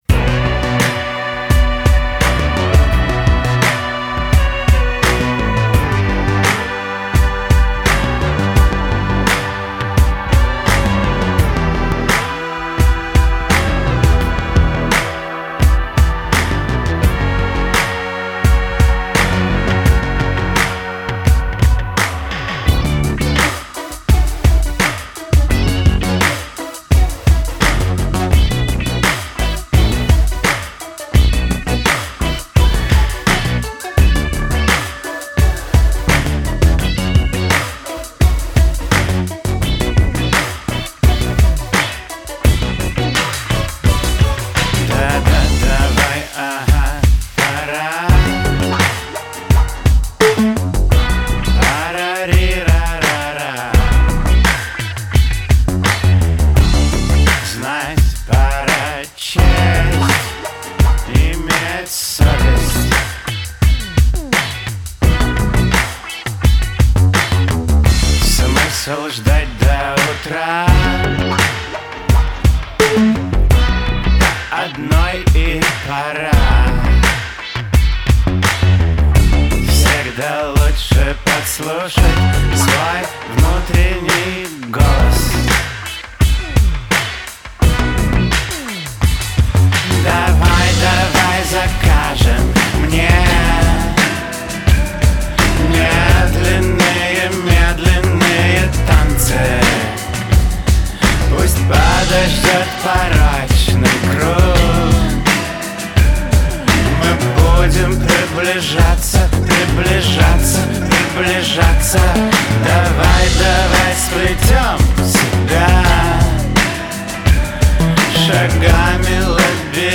Категория: Rock 2016